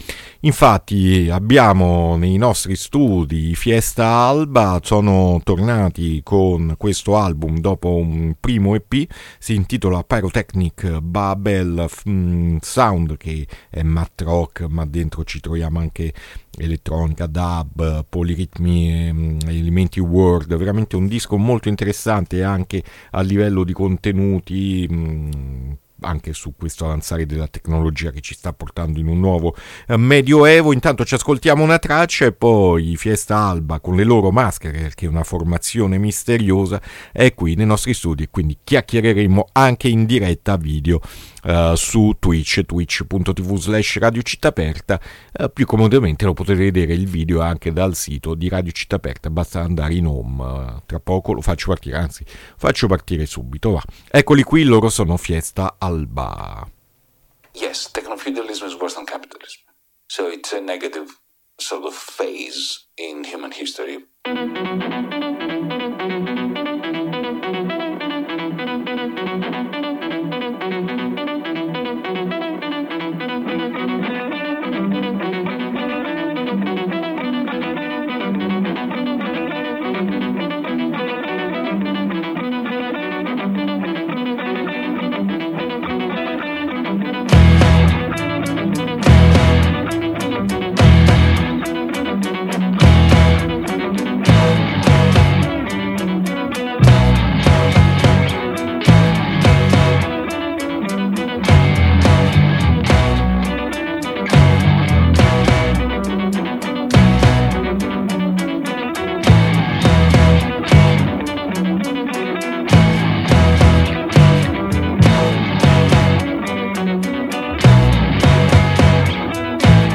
INTERVISTA FIESTA ALBA A MERCOLEDI' MORNING 9-4-2025